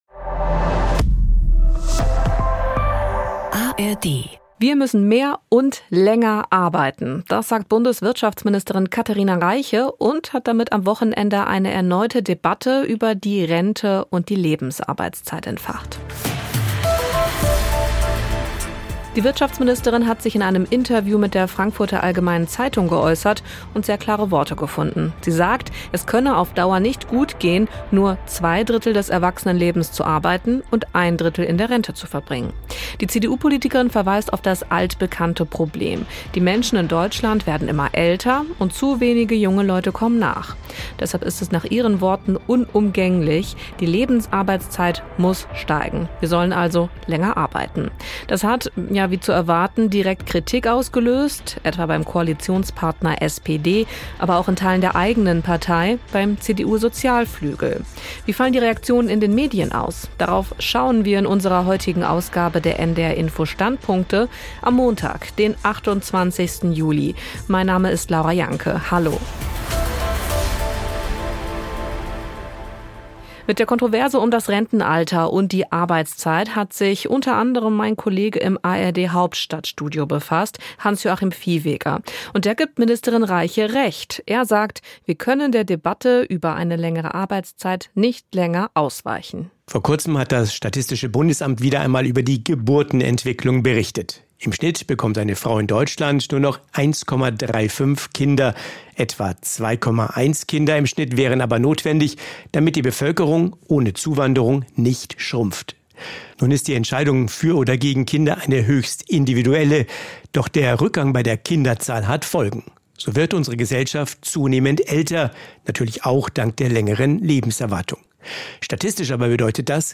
Kommentar aus dem ARD-Hauptstadtstudio und weitere Meinungen aus